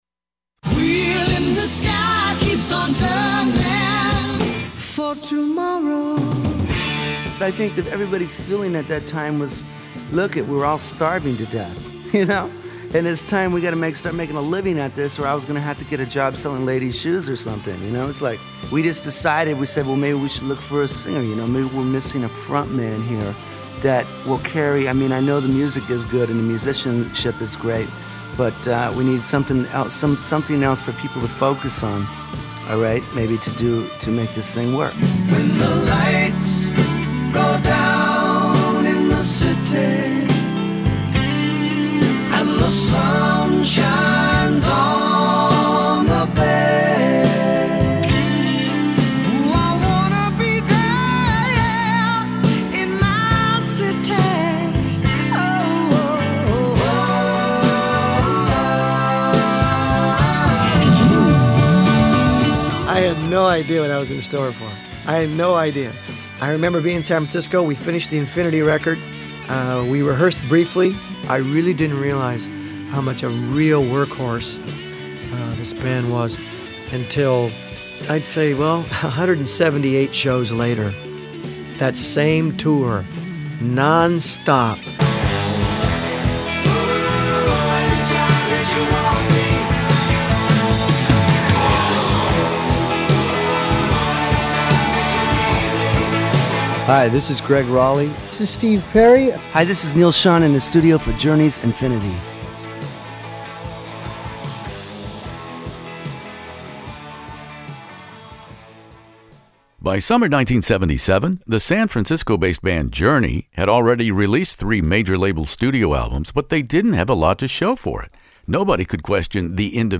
Journey "Infinity" interview with Steve Perry, Gregg Rolie, Neal Schon In the Studio